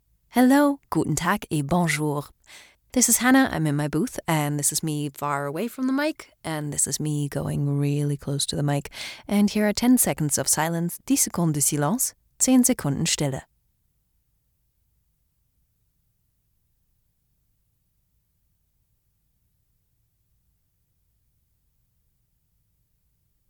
Female
Assured, Authoritative, Character, Confident, Corporate, Engaging, Friendly, Natural, Smooth, Soft, Warm, Versatile
German, Ruhrpott (native)
Microphone: Sennheiser MKH 416